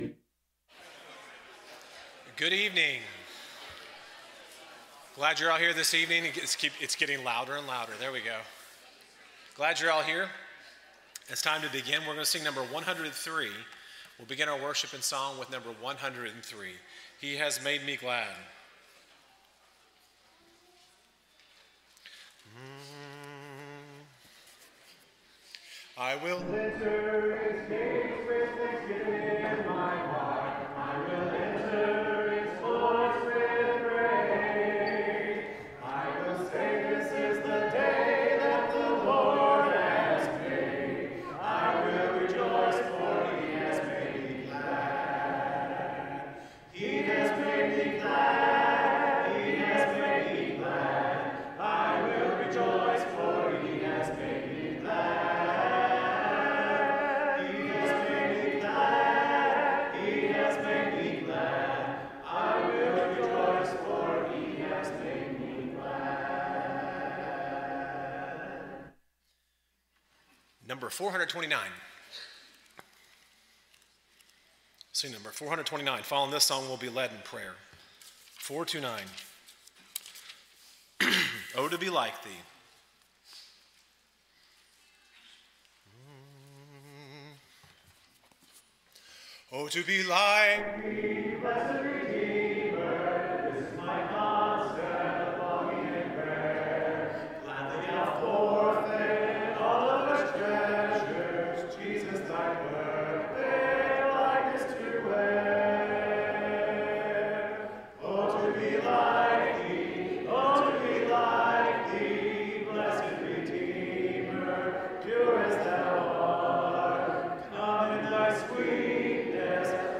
Matthew 12:36, English Standard Version Series: Sunday PM Service